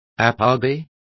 Complete with pronunciation of the translation of apogee.